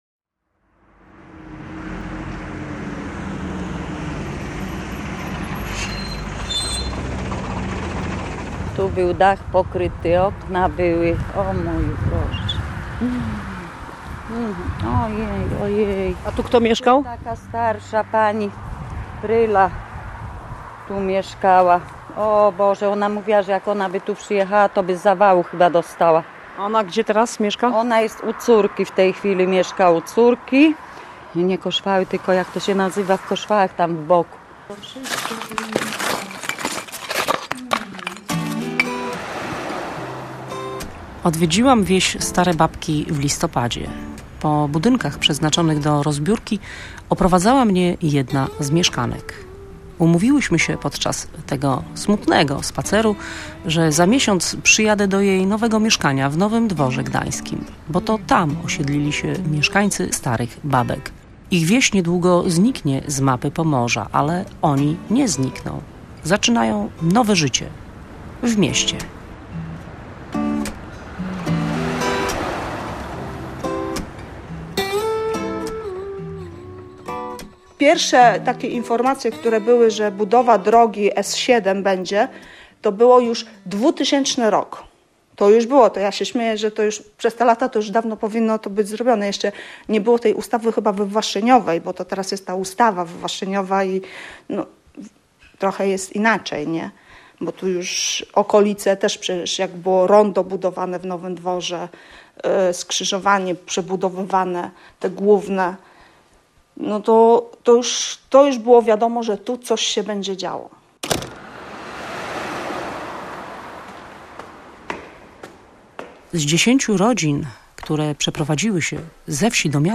To jest reportaż o zmianie.